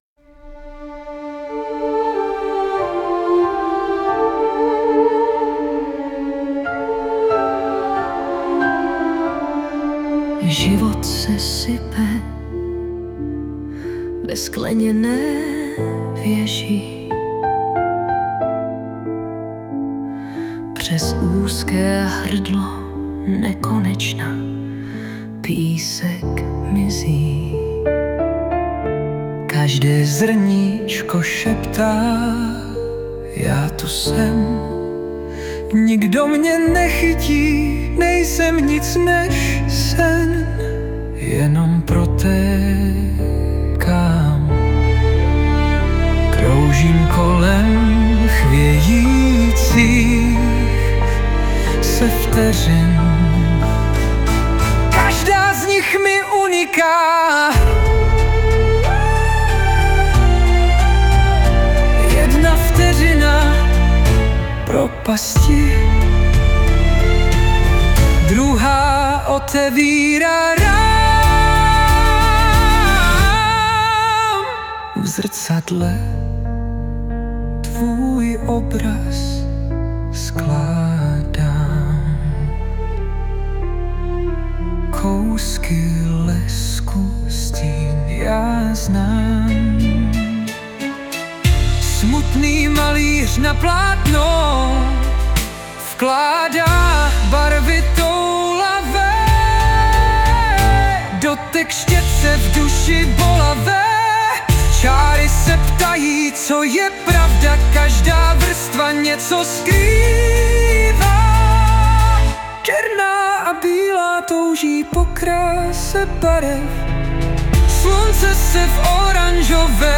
2025 & Hudba, zpěv a obrázek: AI
a máš i cit vybrat zpěvy - jsou srozumitelné, procítěné a poklona...:))*